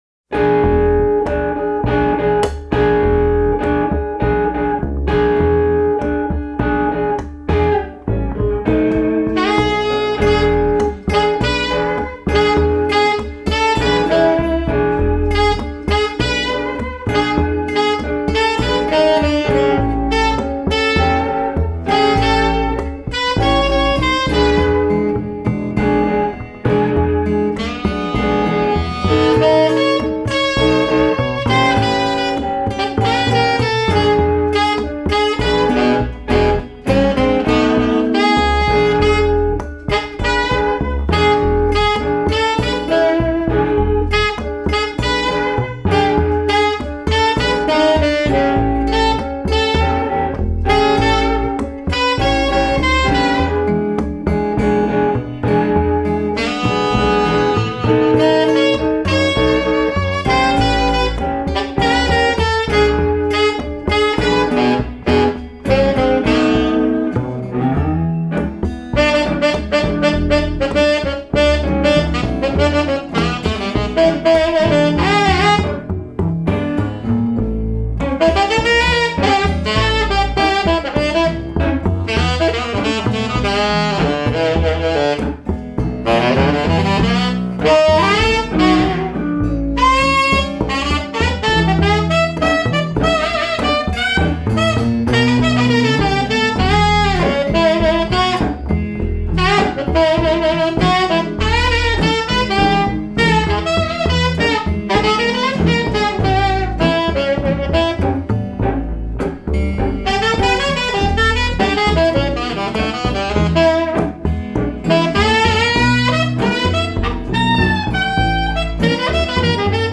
acoustic guitar
electric guitar
sax
bass
Recorded live at the No Fun Club in Winnipeg